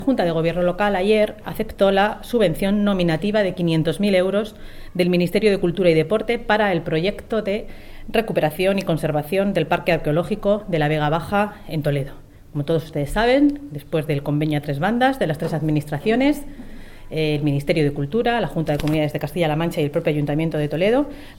La portavoz del equipo de Gobierno, Noelia de la Cruz, ha informado este viernes en rueda de prensa de los asuntos tratados en la Junta de Gobierno local, entre los que destacan diferentes cuestiones relacionadas con la Semana Grande del Corpus Christi, así como con el proyecto de recuperación y puesta en valor del espacio arqueológico de la Vega Baja.
AUDIOS. Noelia de la Cruz, portavoz del equipo de Gobierno